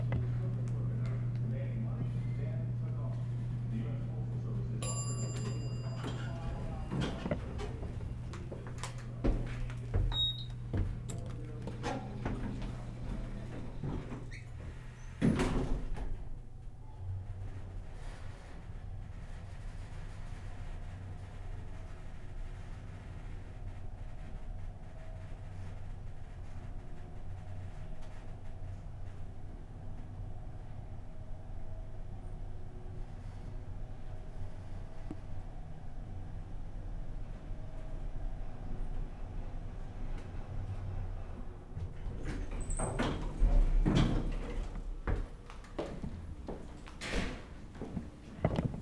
Elevator ride
ding doors elevator sound effect free sound royalty free Sound Effects